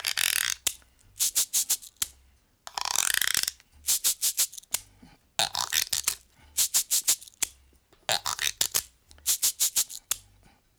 88-PERC-03.wav